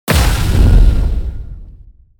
spell-impact-3.mp3